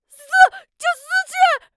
c02_小孩喊着火啦2.wav